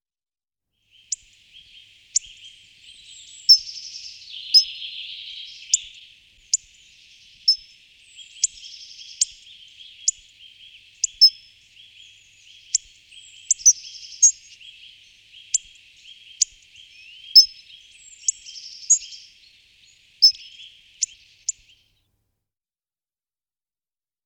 Frosone
Coccothraustes coccothraustes
La nota più comune è un acuto e improvviso ‘pzik-pzik’. Il canto è raramente udito.
Frosone.mp3